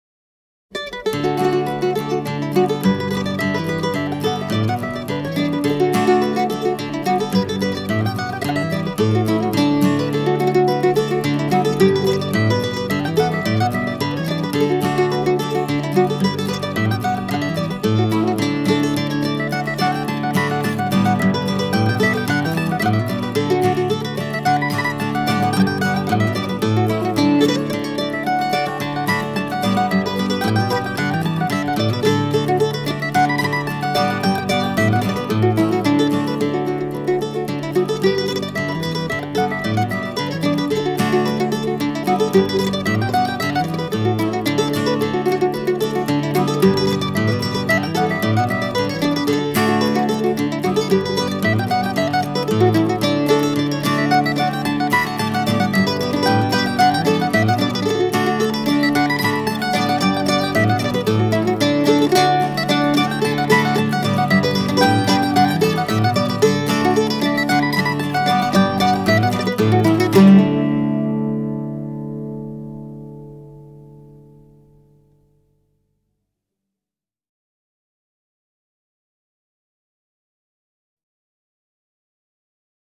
And so we come to what should have been my initial thought on how to celebrate St. Patrick’s Day – with real Irish music.